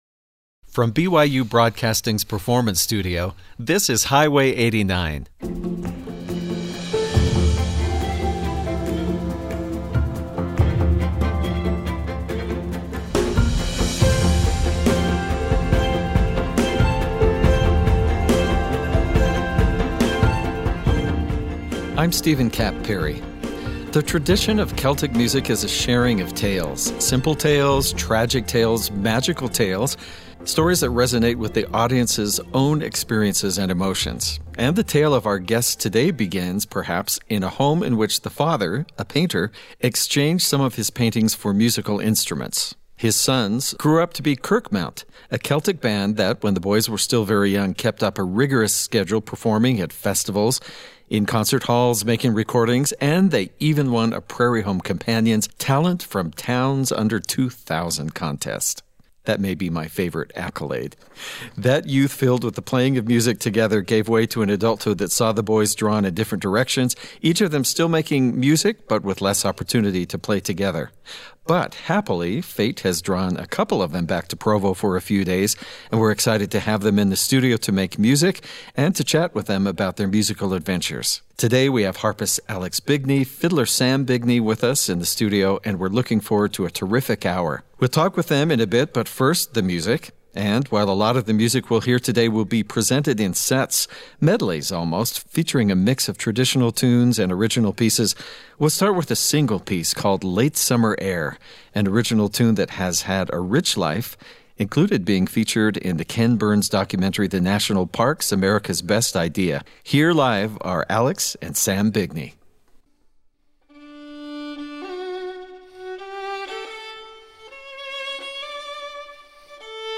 fiddler
Celtic
Harpist
medleys featuring traditional tunes and original pieces